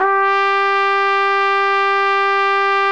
Index of /90_sSampleCDs/Roland L-CD702/VOL-2/BRS_Tpt Cheese/BRS_Cheese Tpt